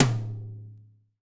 TOM TOM1904L.wav